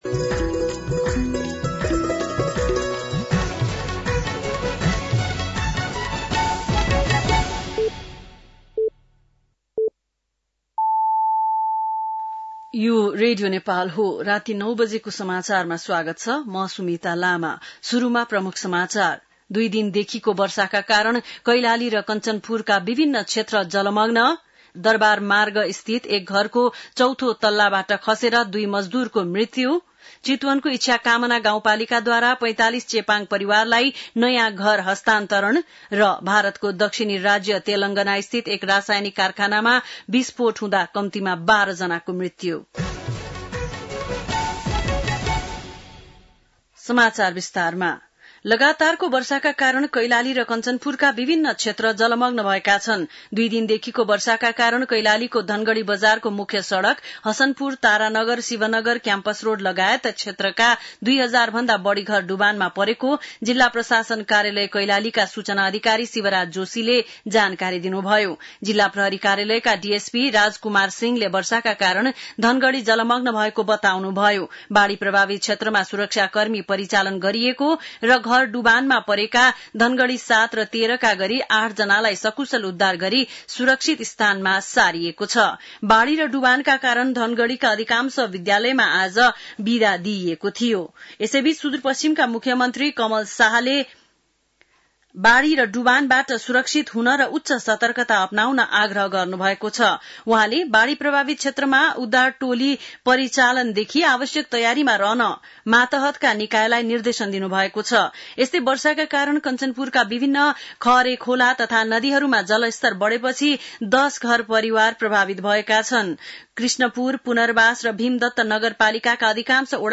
बेलुकी ९ बजेको नेपाली समाचार : १६ असार , २०८२
9-PM-Nepali-NEWS-3-16.mp3